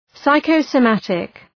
Προφορά
{,saıkəʋsəʋ’mætık}